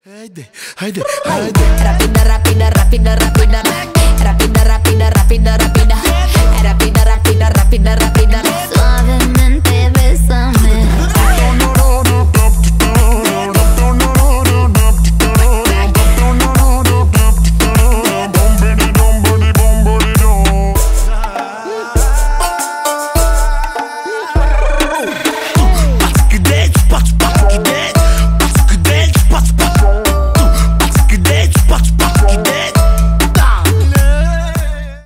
Рэп и Хип Хоп
латинские